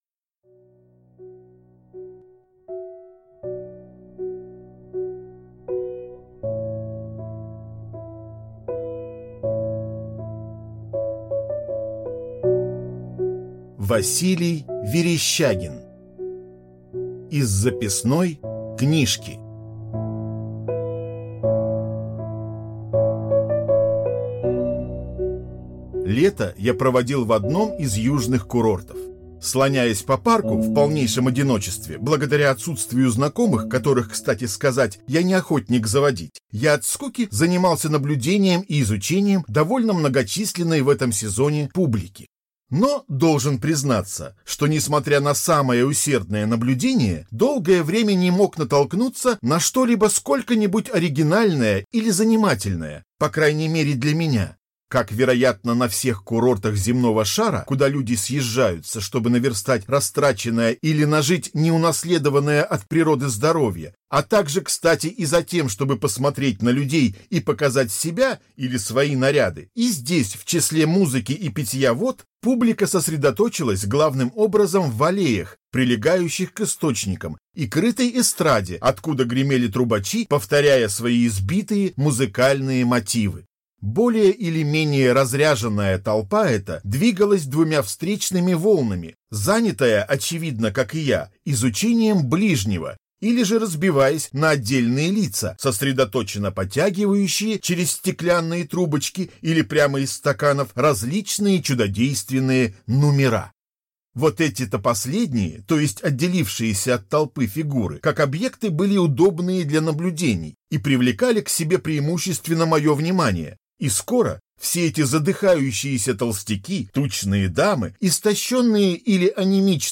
Аудиокнига Из записной книжки | Библиотека аудиокниг